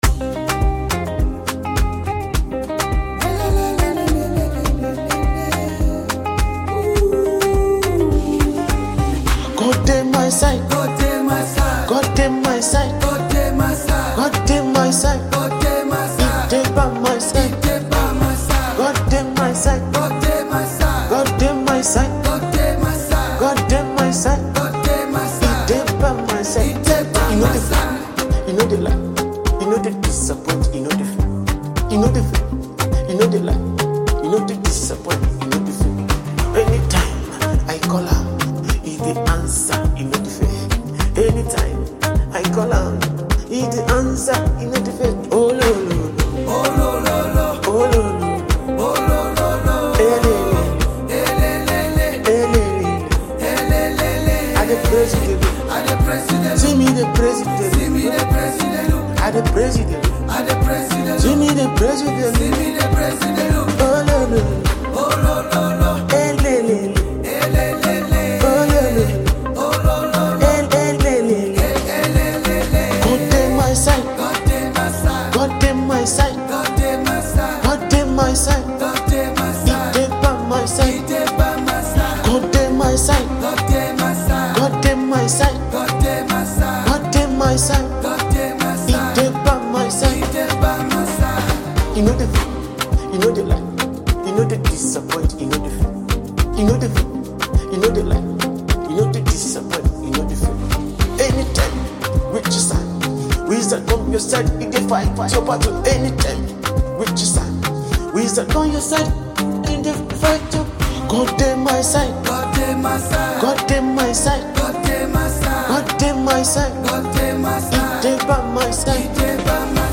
In this uplifting gospel song